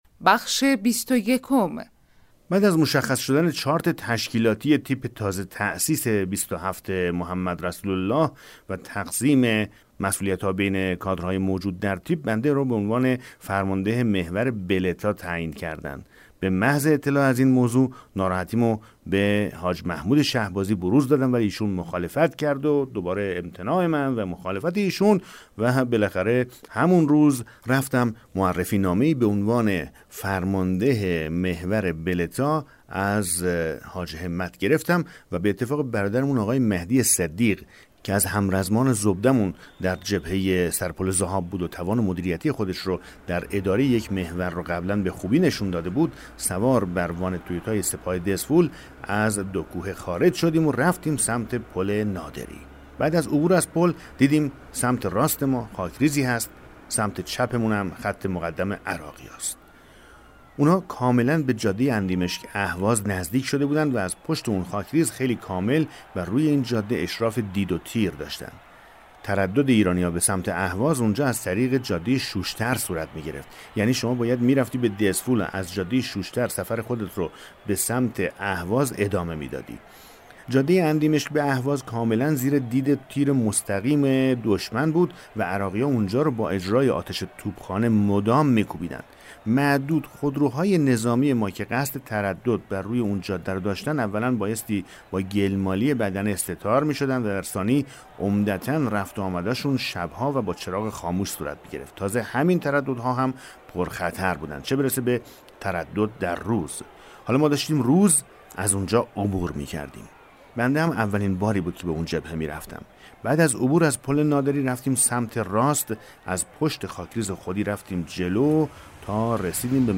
کتاب صوتی پیغام ماهی ها، سرگذشت جنگ‌های نامتقارن حاج حسین همدانی /قسمت 21